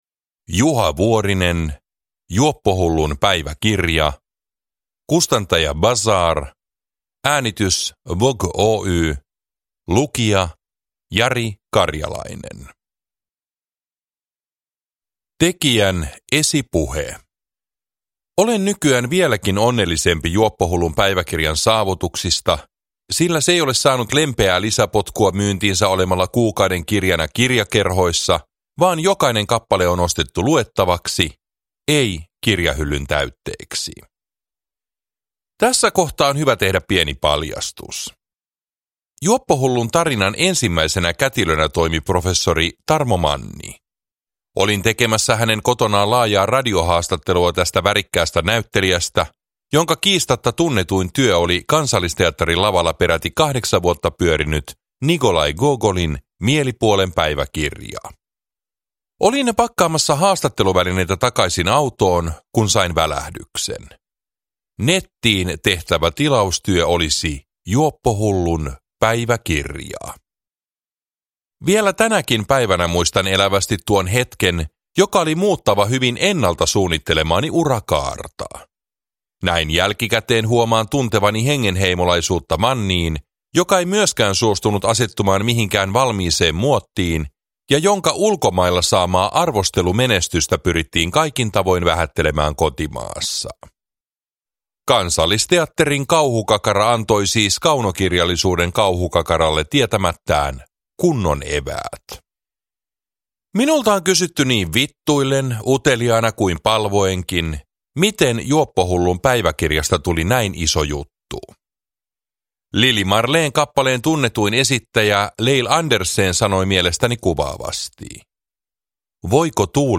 Juoppohullun päiväkirja – Ljudbok